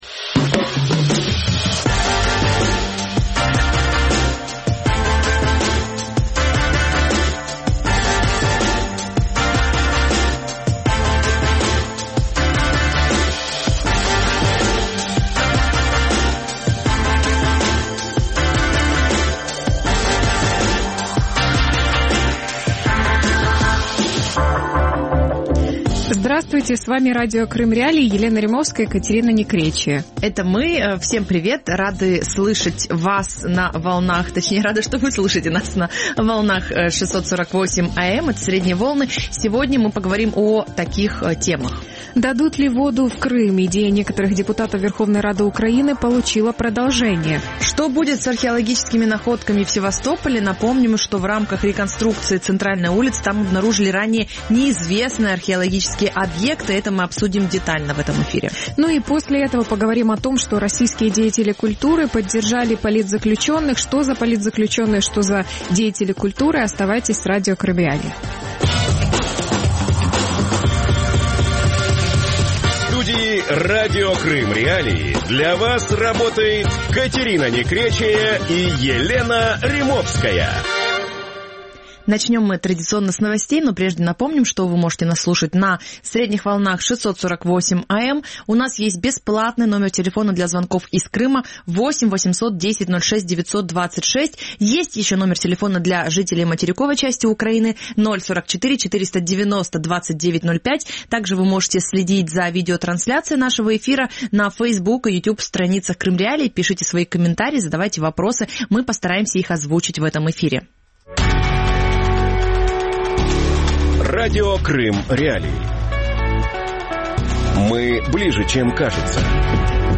Реконструкция центра Севастополя и найденные подземелья | Дневное ток-шоу